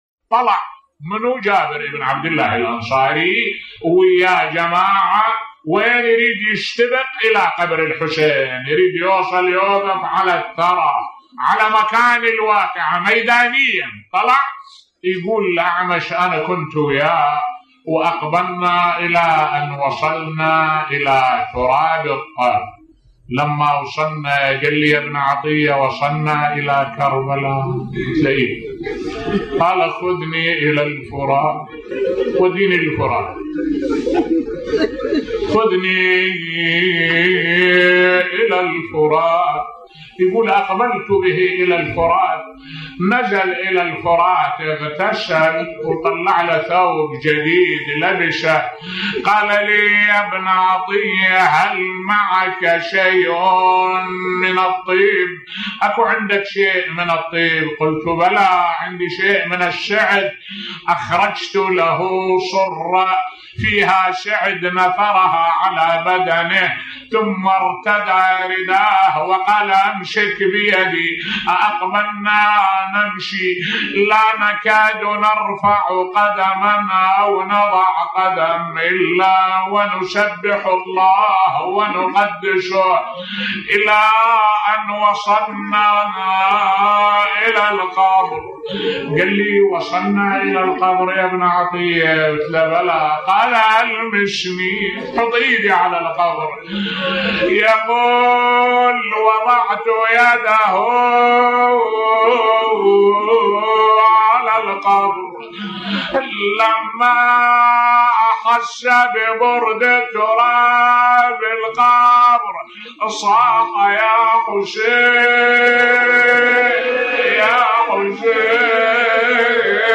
ملف صوتی اول من زار قبر الامام الحسين (ع) بصوت الشيخ الدكتور أحمد الوائلي